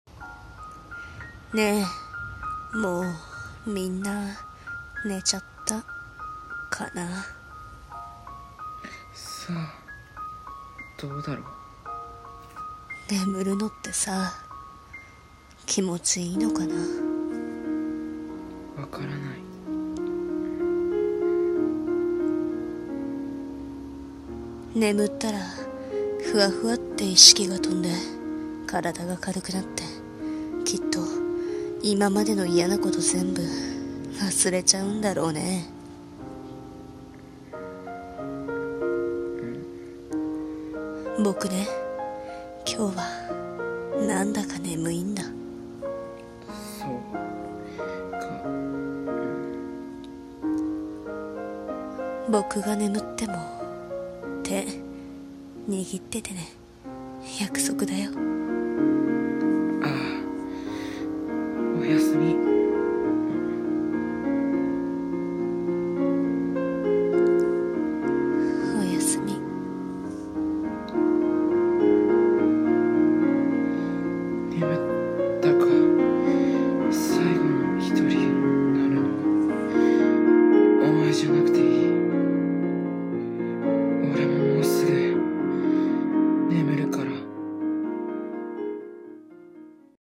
【コラボ用声劇】君と最期と世界の眠り